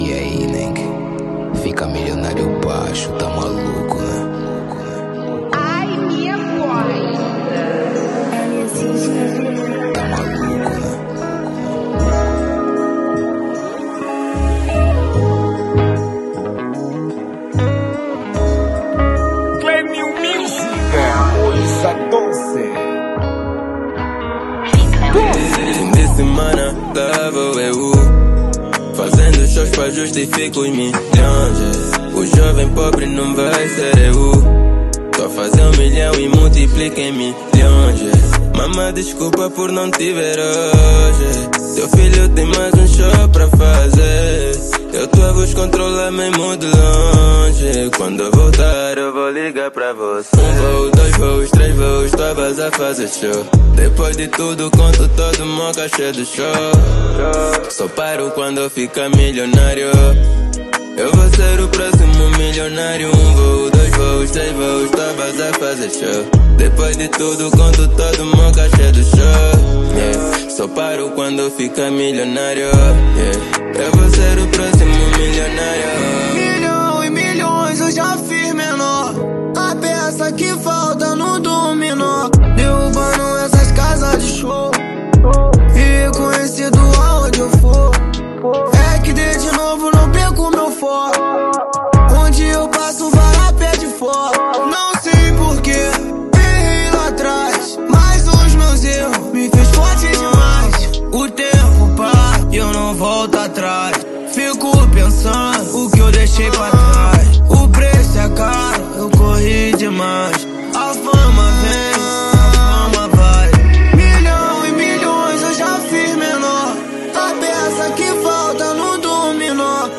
Género: Afro Beat